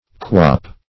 [Local & Vulgar] [1913 Webster] The Collaborative International Dictionary of English v.0.48: Quop \Quop\ (kw[o^]p), v. i. See Quob .